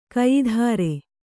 ♪ kayidhāre